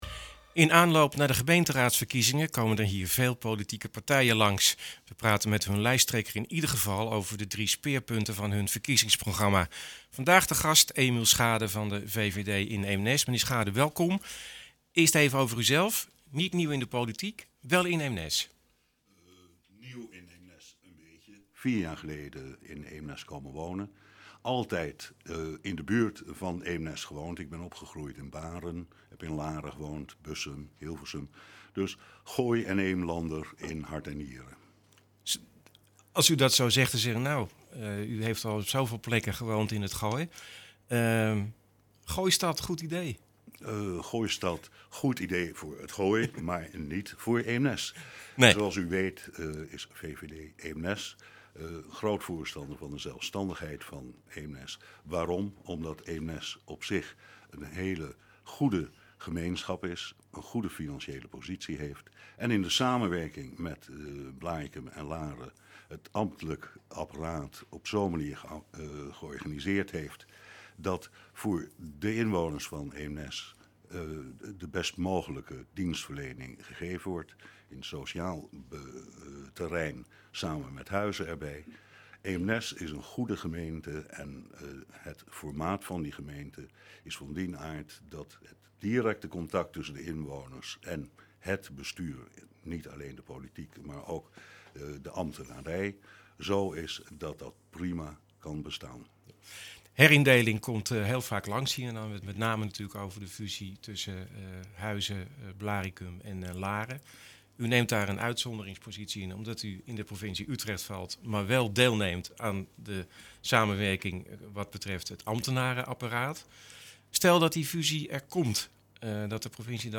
In aanloop naar de gemeenteraadsverkiezingen komen er hier veel politieke partijen langs. We praten met hun lijsttrekker in iedere geval over de drie speerpunten van hun verkiezingsprogramma.